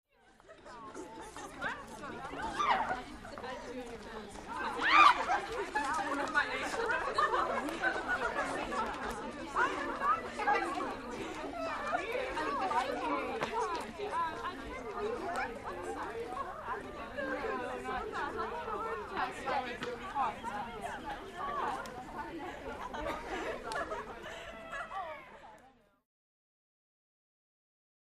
Teenage Girls Walking By, W Giggling And Talking Gossip.